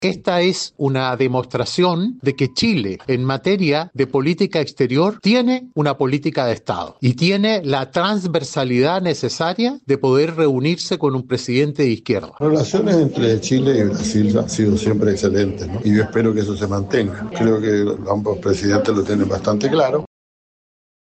De hecho, el presidente de la instancia, Iván Moreira (UDI), dijo que el hecho da cuenta de una política de Estado en materia internacional; mientras que su par socialista, José Miguel Insulza, espera que las buenas relaciones entre ambos países se mantengan durante el próximo periodo presidencial.